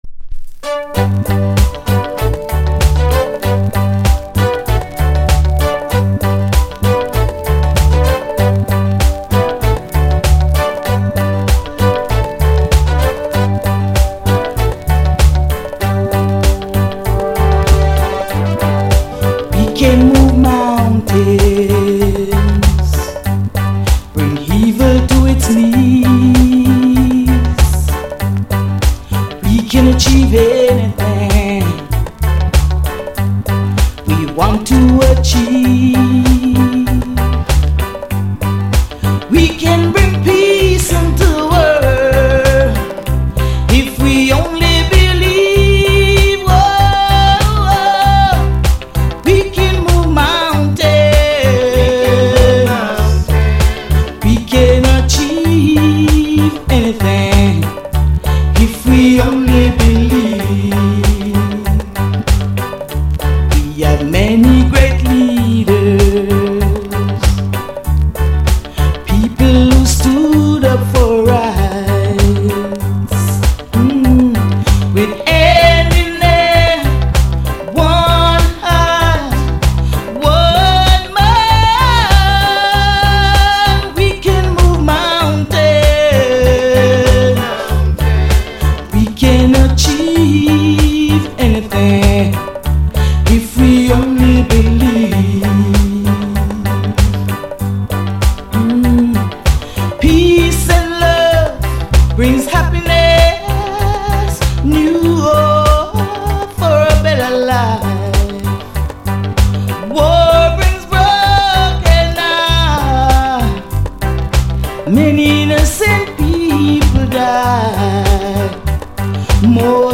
ReggaeAfter90s / Female Vocal Condition EX- Soundclip